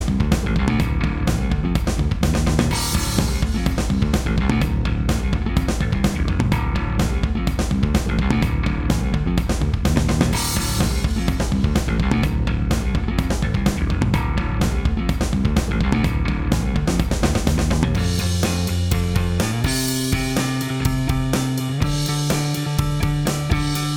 Minus Lead Guitar Rock 3:40 Buy £1.50